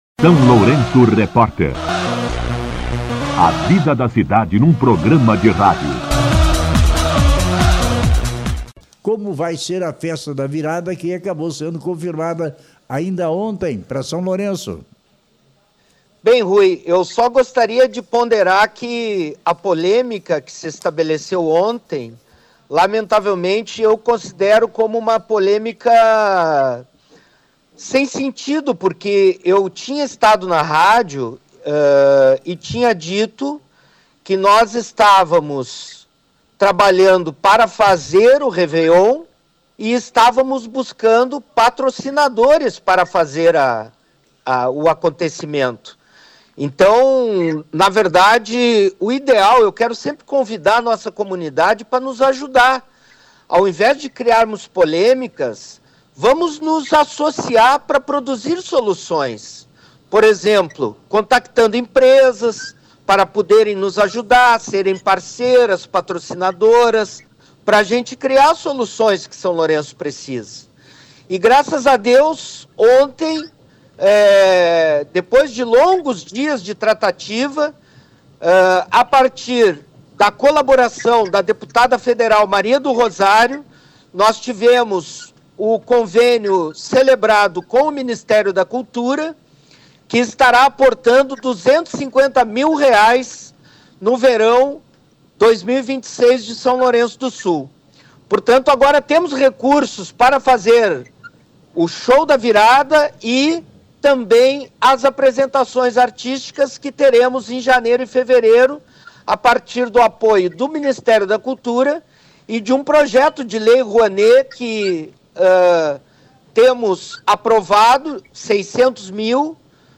Em entrevista ao SLR RÁDIO, na manhã desta quinta-feira (18), Marten também abordou os Shows de Verão no Largo Laura Abreu e o Carnaval Lourenciano.
Entrevista-Zelmute-18.mp3